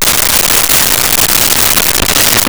Applause Short
APPLAUSE SHORT.wav